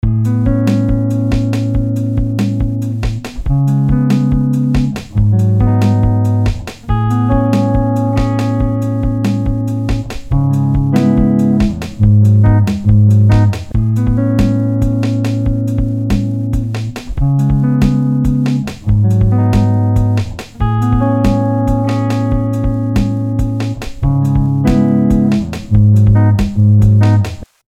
Schritt 2 – die Drums
Innovativ für diesen Stil sind die Snares. Während man in Hip Hop meistens bei 2 und 4 bleibt, orientiert sich der neue Stil am Reggaeton oder Indie.
Um den Takt zu halten, setzen wir die Kick in der ersten Hälfte des Loops auf jeden vierten Step, ähnlich wie bei Electro. In der zweiten Hälfte des Loops setzen wir sie etwas typischer für Hip Hop oder RnB.
Der neue Stil lebt von der Kombination von einfachen, elektronisch angehauchten Rhythmen und exotischer Percussion. Dadurch wird der Beat tanzbar. Die Geschwindigkeit ist meistens schneller als bei typischem Hip Hop gewählt.